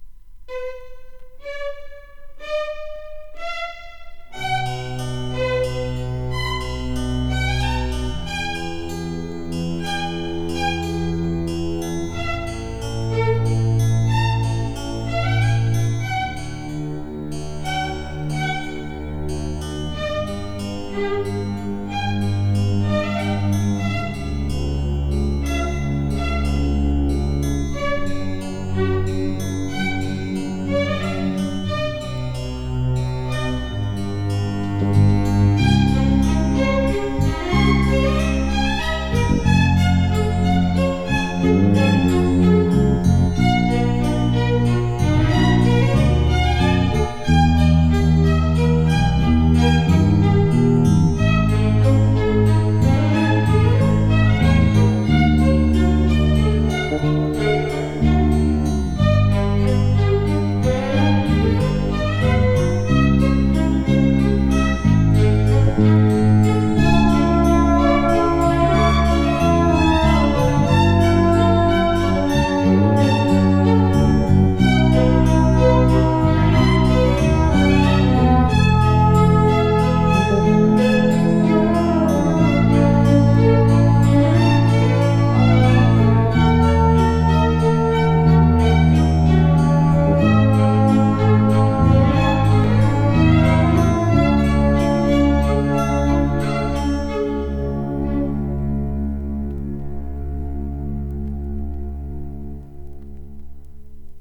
Soundtrack, Western